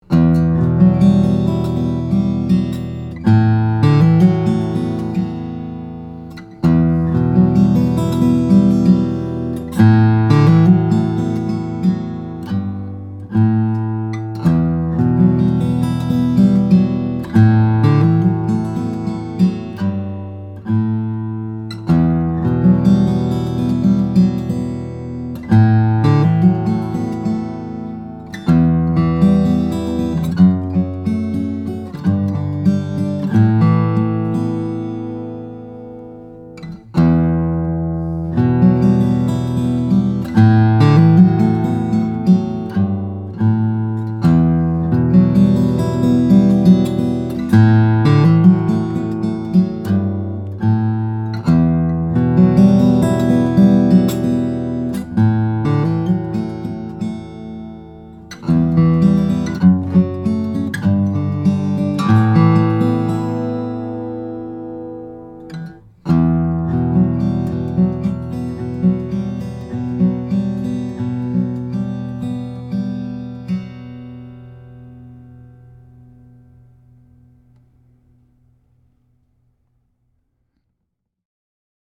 Abalone appointments, Abalone dove inlays, and an exquisitely effortless playability all combine for maximum resonance.